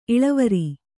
♪ iḷavari